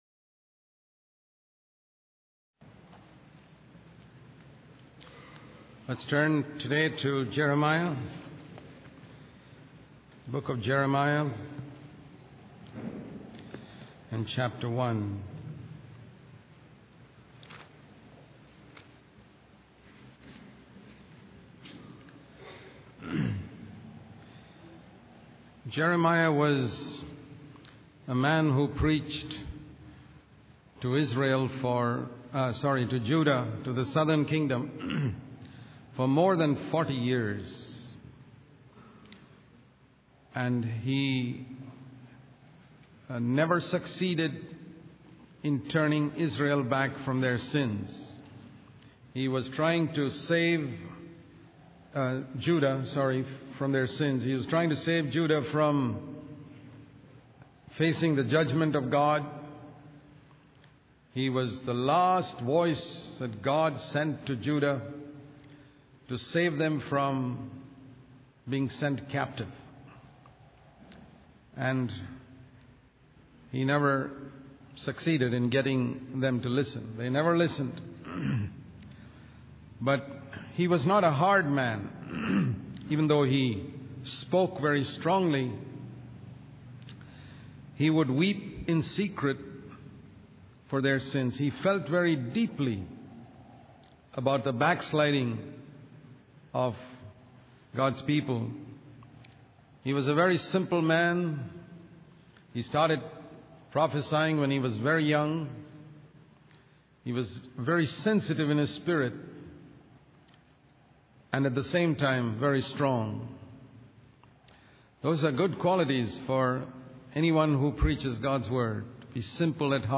In this sermon, the preacher begins by expressing his broken heart over the suffering of children and babies in the streets. He highlights the lack of good examples among leaders, leading young people astray.